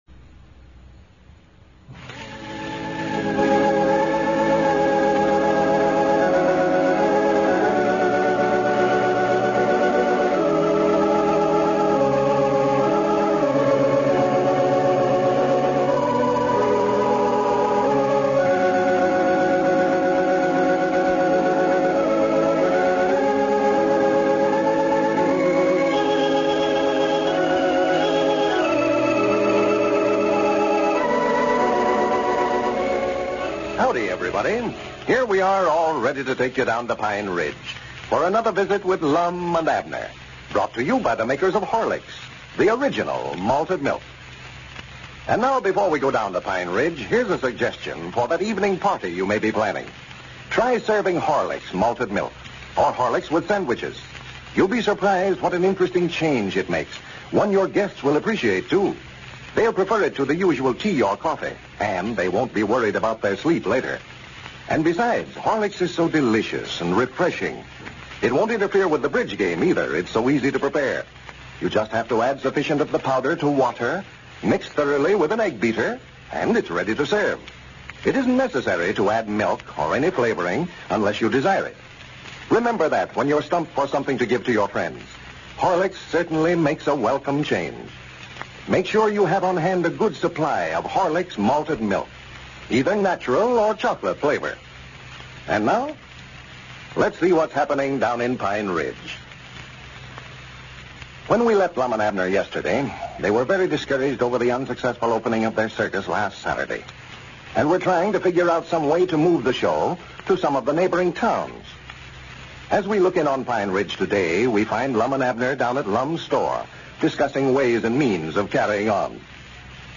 Lum and Abner! A classic radio show that brought laughter to millions of Americans from 1931 to 1954.